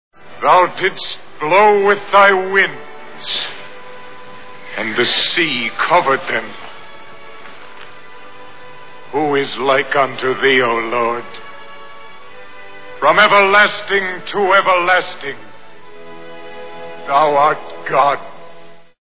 Ten Commandments Movie Sound Bites